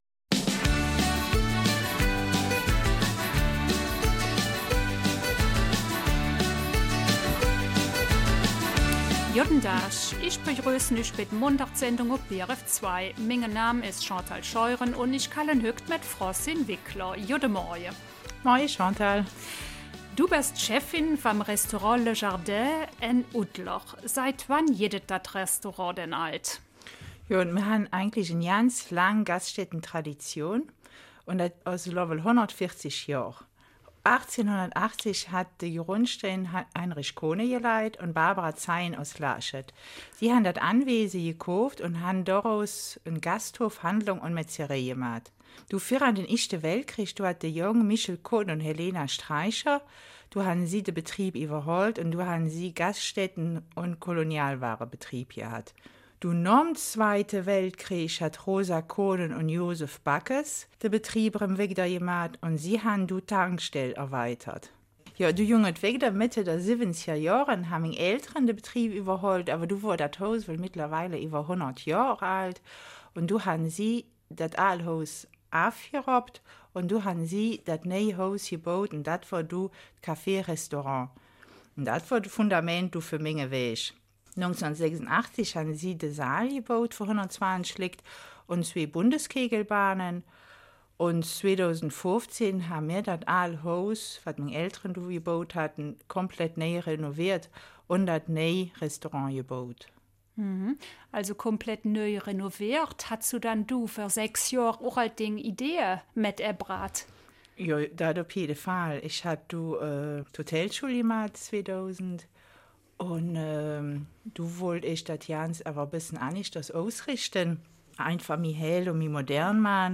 Eifeler Mundart: ''Le Jardin'' Oudler 06.